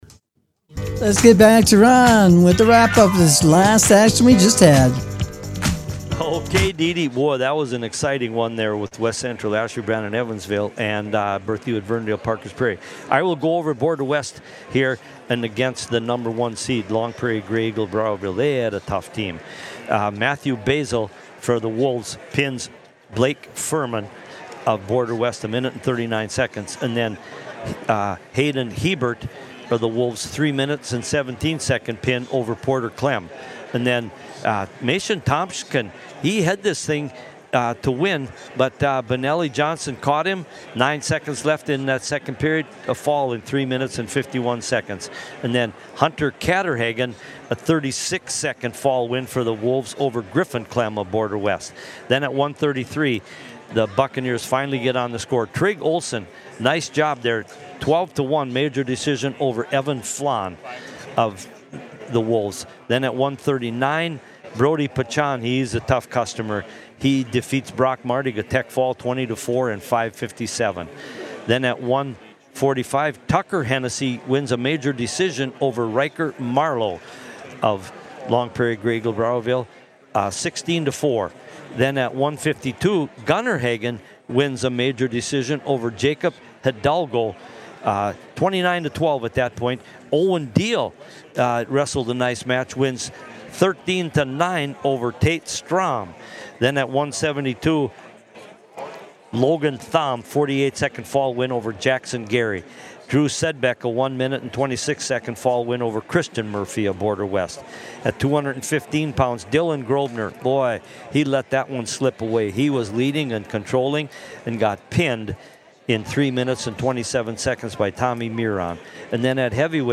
SEMIFINALS WRAP UP